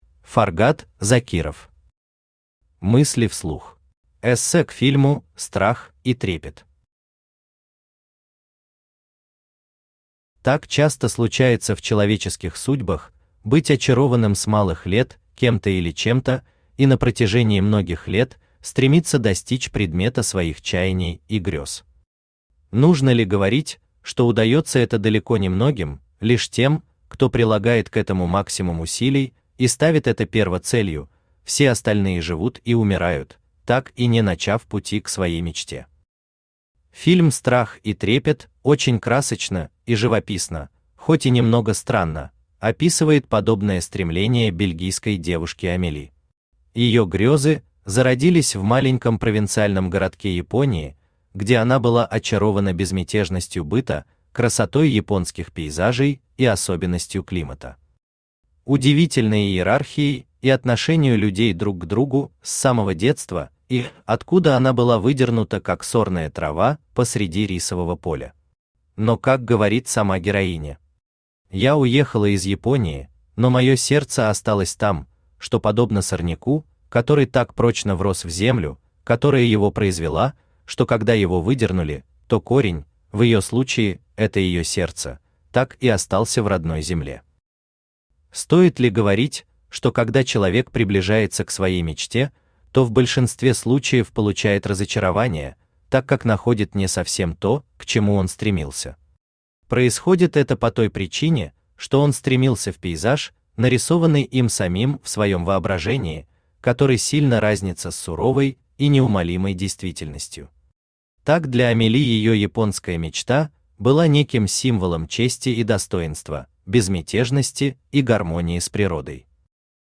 Аудиокнига Мысли вслух | Библиотека аудиокниг
Aудиокнига Мысли вслух Автор Фаргат Закиров Читает аудиокнигу Авточтец ЛитРес.